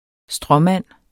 Udtale [ ˈsdʁʌ- ]